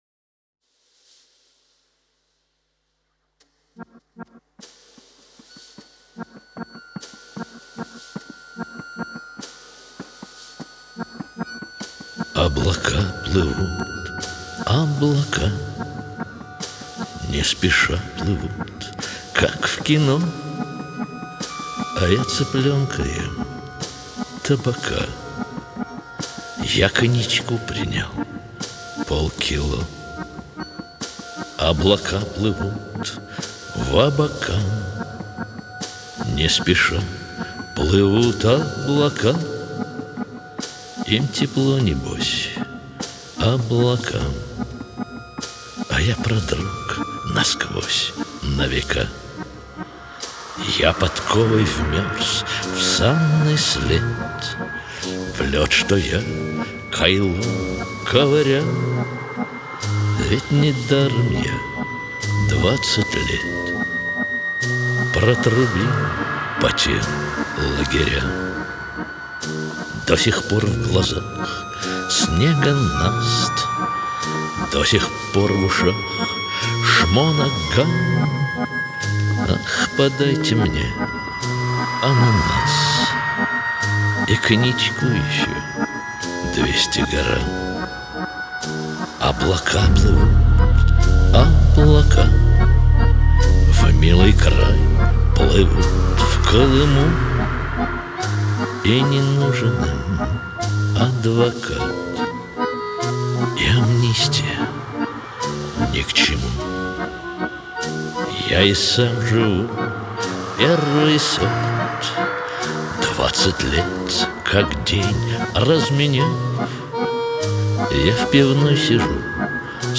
Жанр: авторская песня, rock